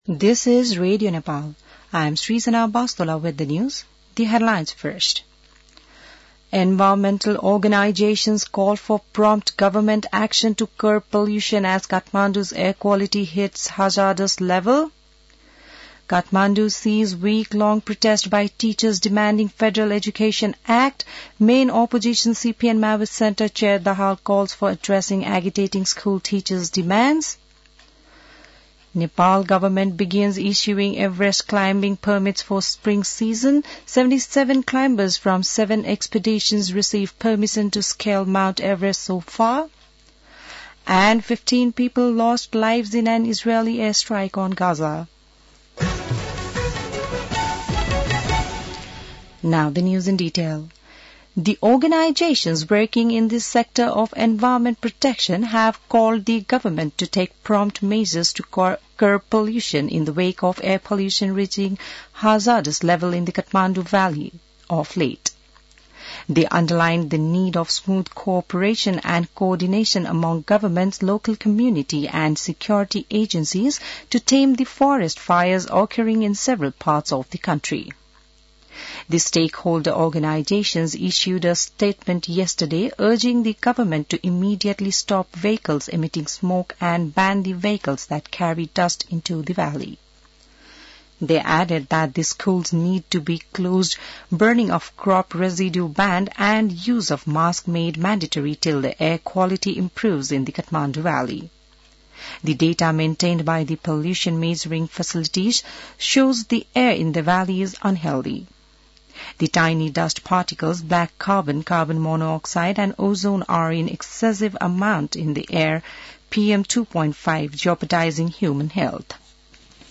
बिहान ८ बजेको अङ्ग्रेजी समाचार : २५ चैत , २०८१